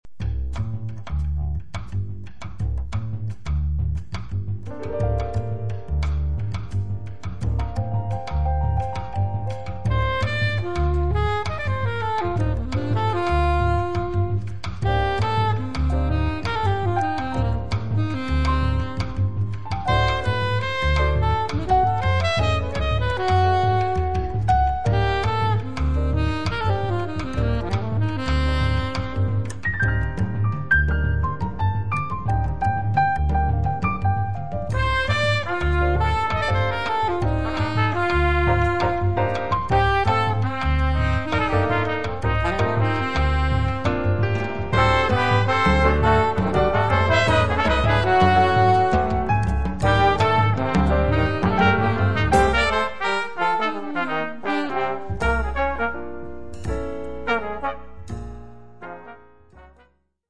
Tromba
trombone
Piano
Contrabbasso
Batteria
vivace finale in controtempo